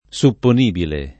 [ S uppon & bile ]